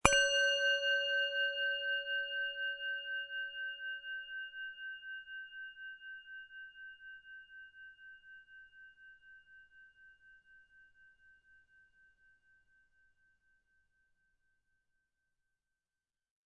singingbowl.mp3